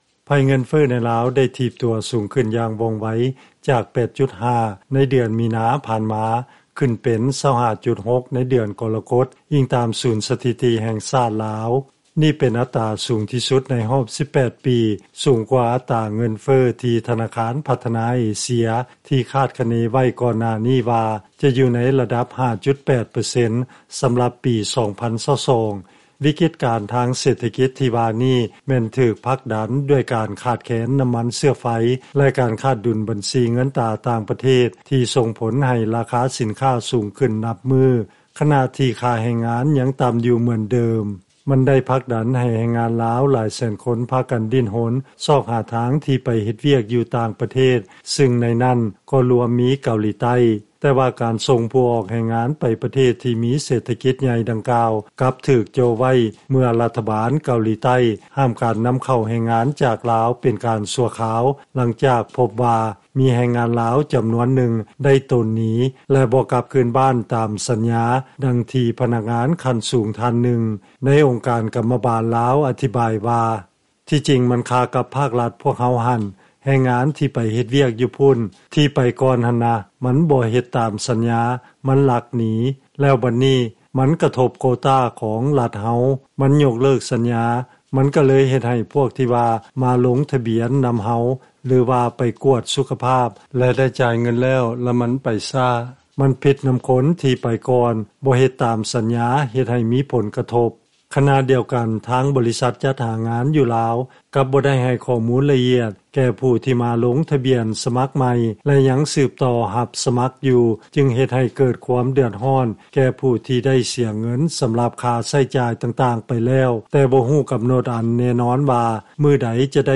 ເຊີນຟັງລາຍງານ ແຮງງານລາວ ທີ່ໄປເຮັດວຽກຢູ່ເກົາຫຼີໃຕ້ ສ້າງບັນຫາ ສົ່ງຜົນກະທົບຕໍ່ແຮງງານຢູ່ໃນລາວ ທີ່ຢາກຈະໄປເຮັດວຽກເຊັ່ນກັນ